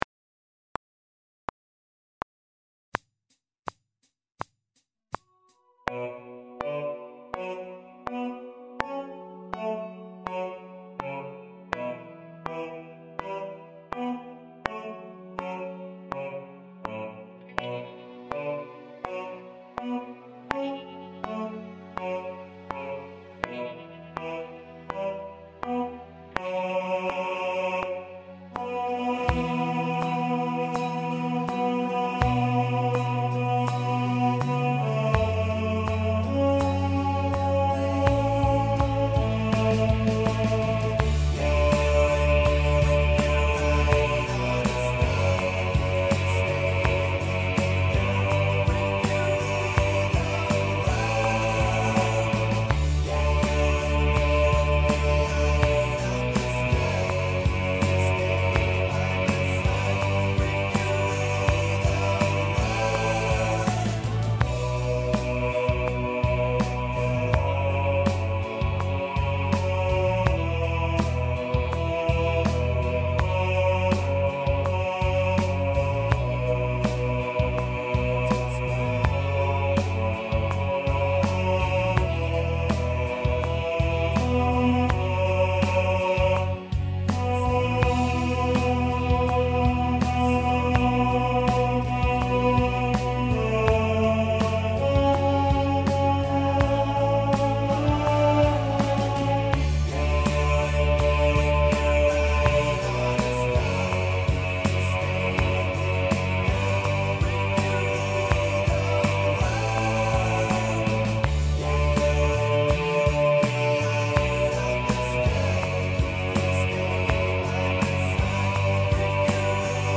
Rule The World – Bass | Ipswich Hospital Community Choir